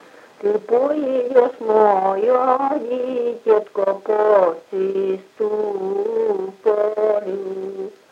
Мягкое цоканье (совпадение литературных аффрикат /ц/ и /ч’/ в мягком /ц’/)
/по-то-му” же-ты” ро-зы-до”-л’иии-ц’уу  шыы-роооо”-ко-му:/